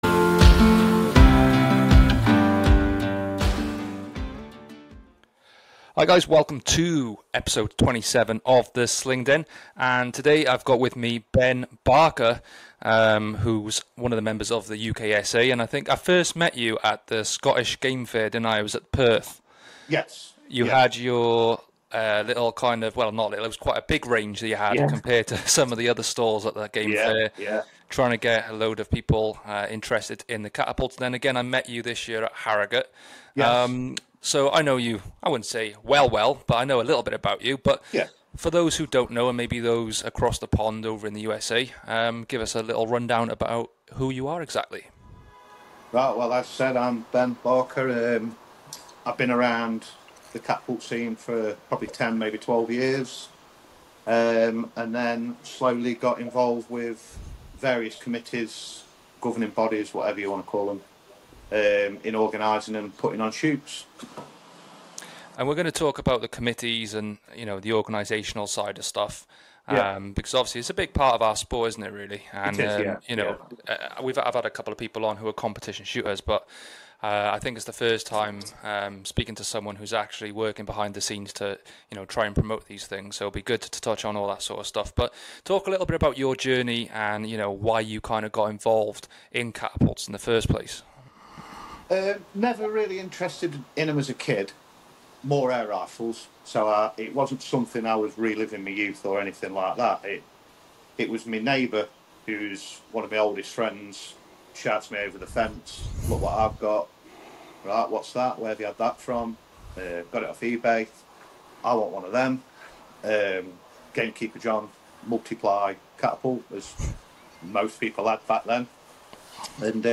The show where we talk everything Slingshots! Join me as I speak to guests from all over the world about Slingshots, we cover topics like competition shooting, hunting, tips and tricks and how the tools we use are made.